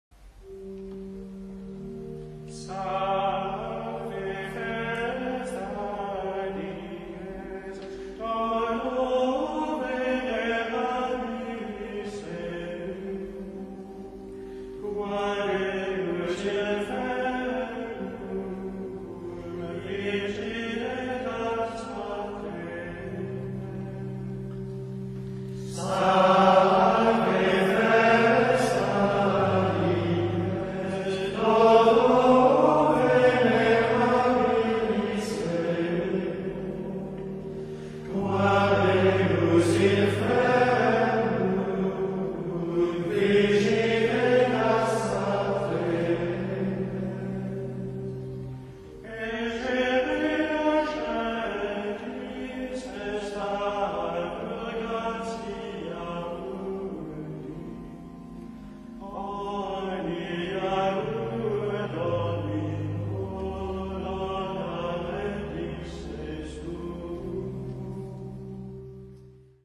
Benedictine Monks of the Abbey Saint-Maurice and Saint-Maur at Clervaux – Gregorian Chant
Люксембург, 1960 г. Иногда нежный аккомпонимент органа может придать Григорианскому пению еще большую умиротворительность...